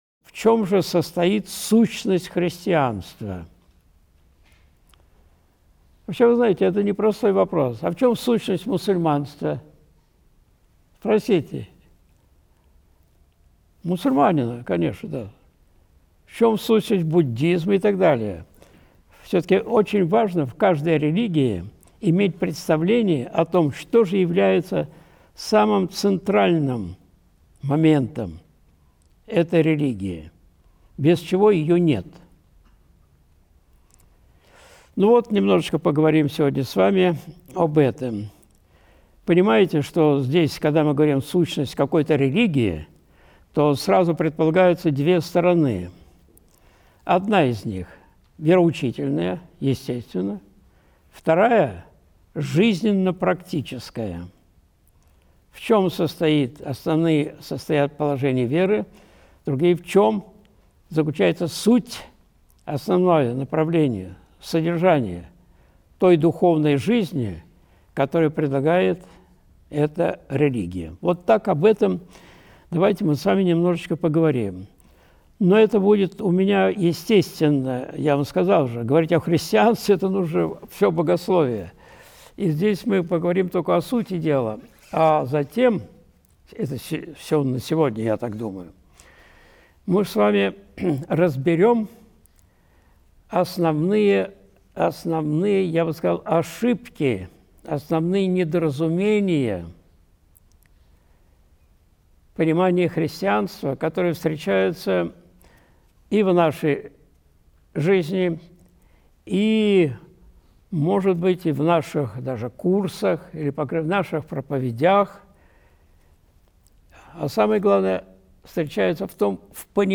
Сущность христианства (Лекция 2. Апологетика, 30.01.2026)
Видеолекции протоиерея Алексея Осипова